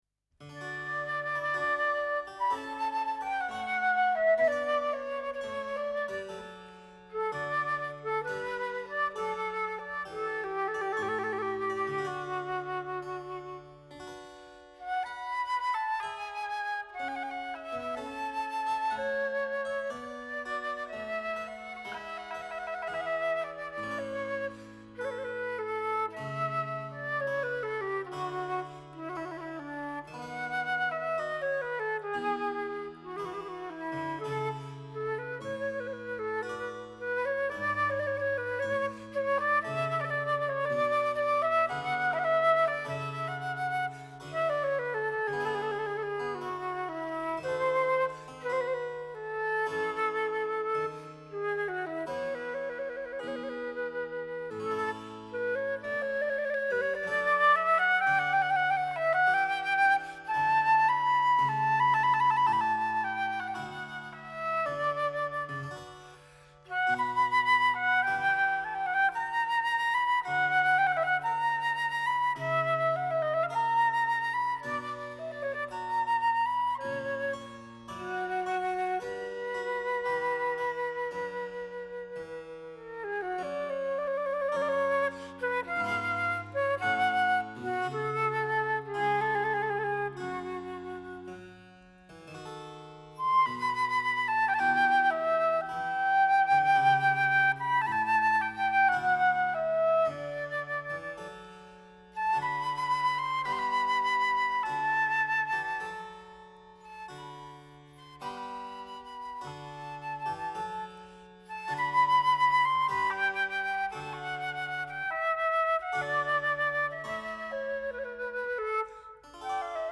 flauto
clavicembalo
Musica Classica / Antica